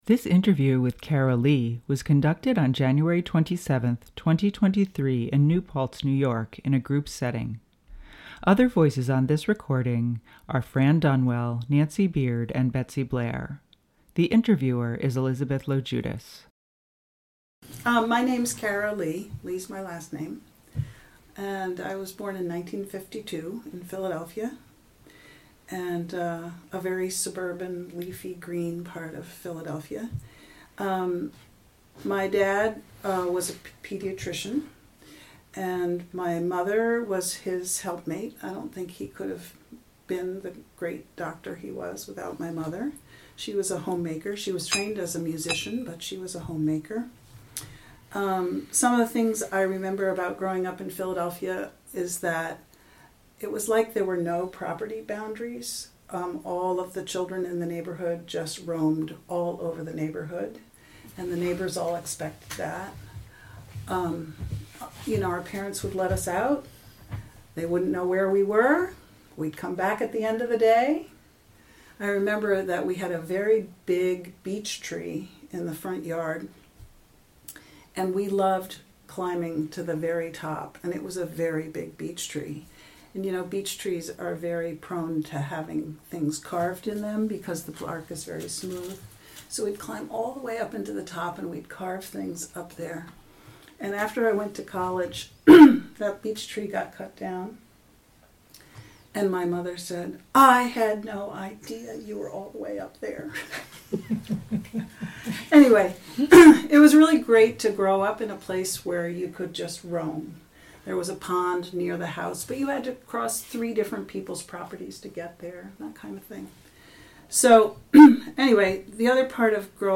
Women of the River Oral Histories